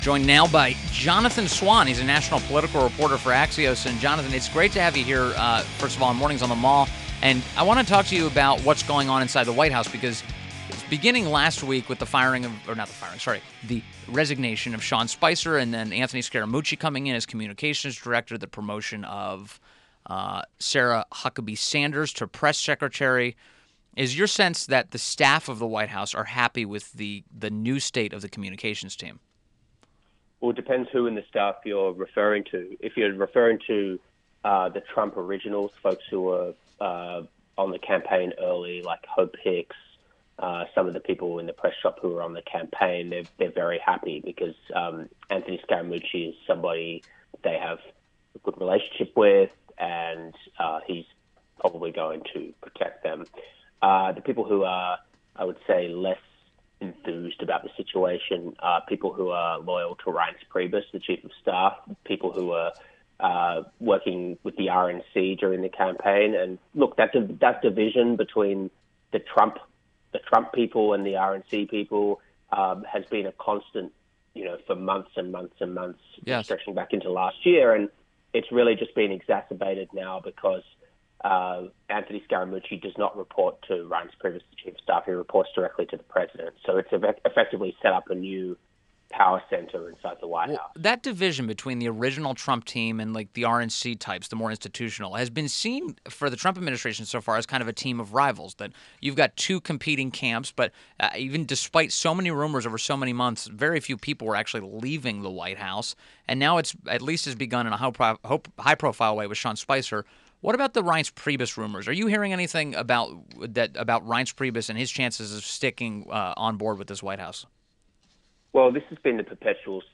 INTERVIEW - JONATHAN SWAN - National political reporter for Axios